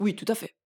VO_ALL_Interjection_17.ogg